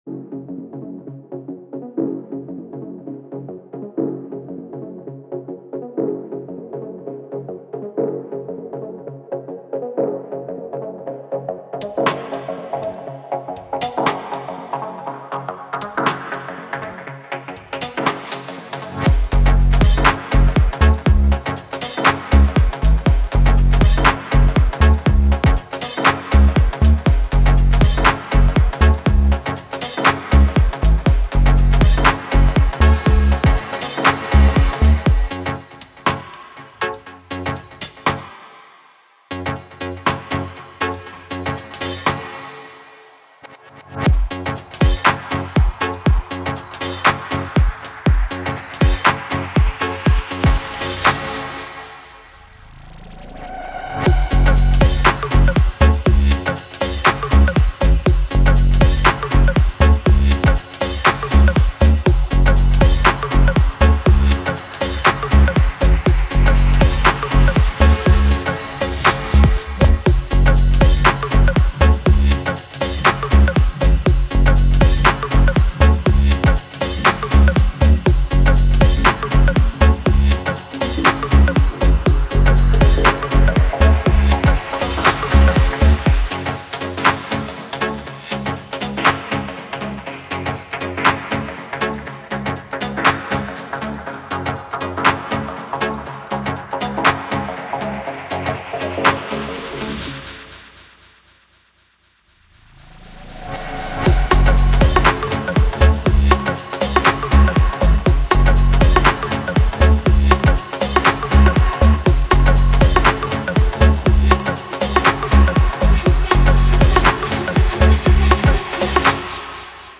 Cold, deep club track with tension and slight agressiveness.